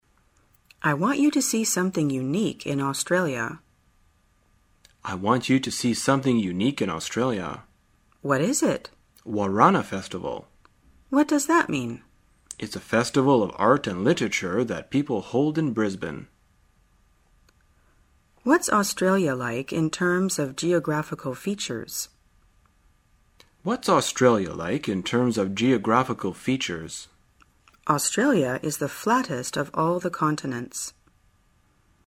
旅游口语情景对话 第355天:如何描述澳洲之旅